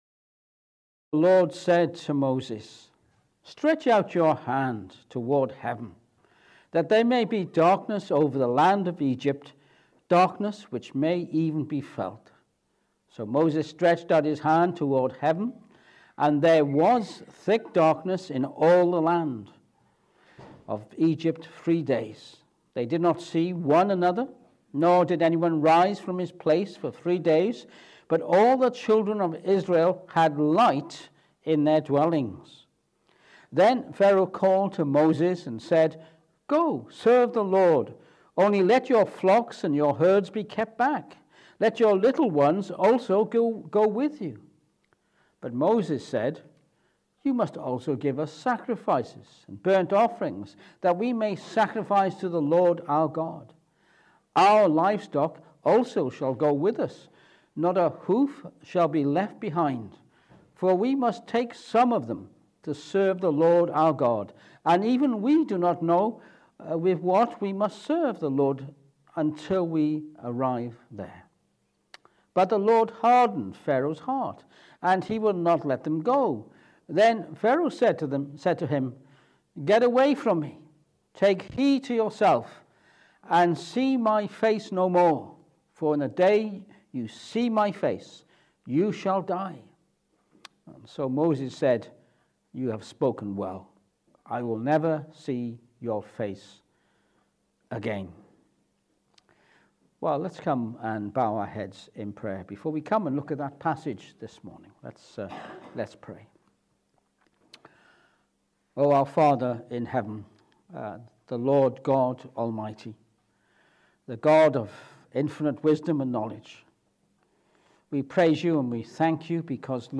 Exodus 10:21-29 Service Type: Morning Service We turn to Exodus chapter 10 this morning